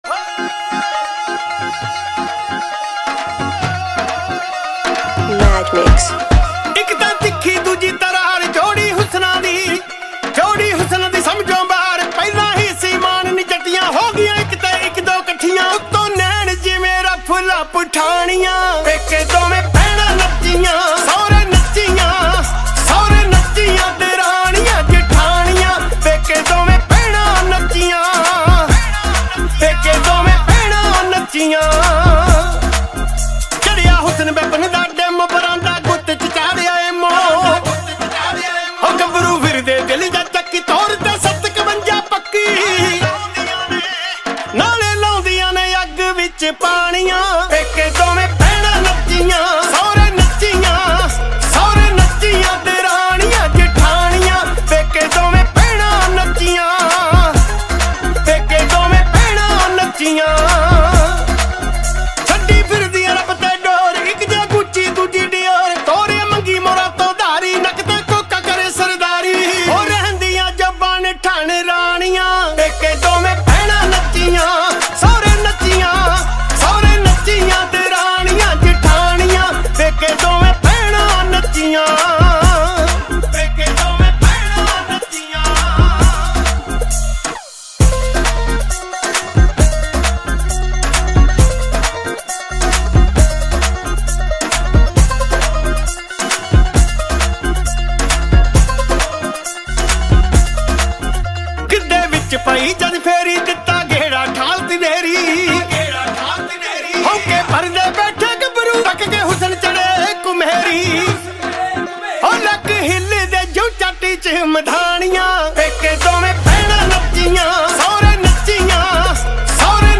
Mp3 Files / Bhangra /